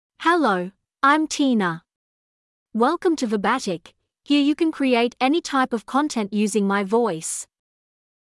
Tina — Female English (Australia) AI Voice | TTS, Voice Cloning & Video | Verbatik AI
FemaleEnglish (Australia)
Tina is a female AI voice for English (Australia).
Voice sample
Tina delivers clear pronunciation with authentic Australia English intonation, making your content sound professionally produced.